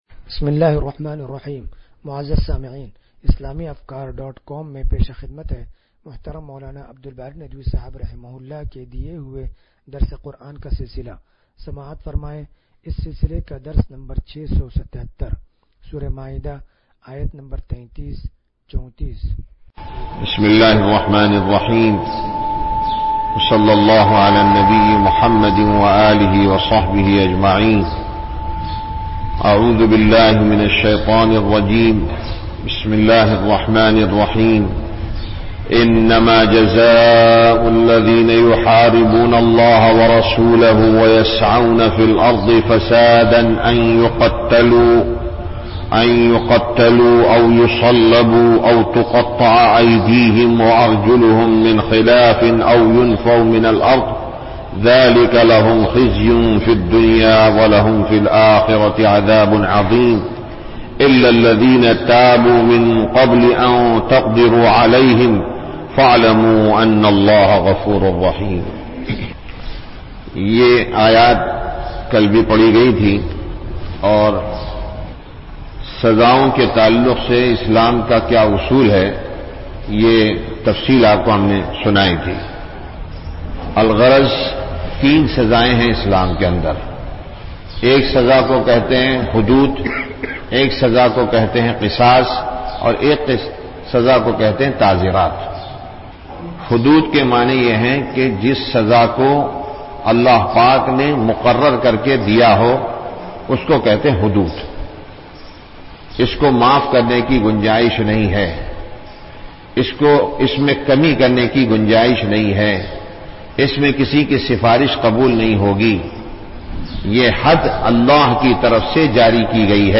درس قرآن نمبر 0677
درس-قرآن-نمبر-0677.mp3